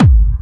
Kick i.wav